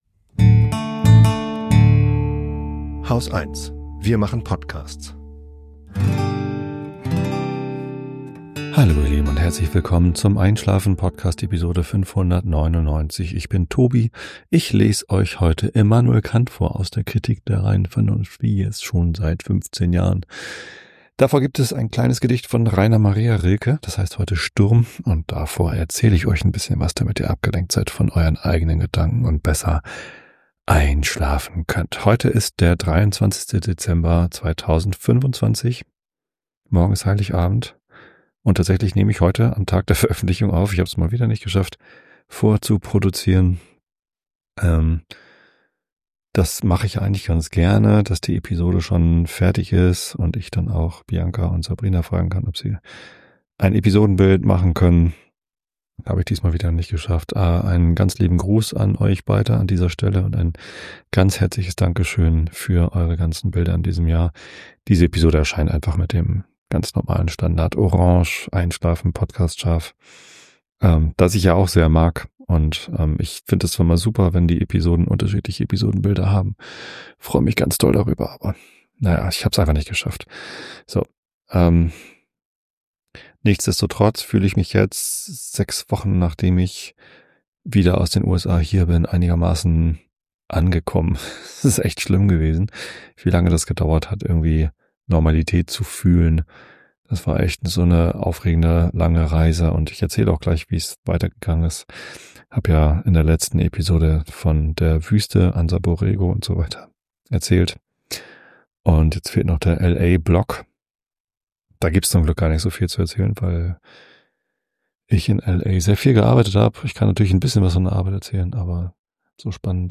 Monologe zum Entspannen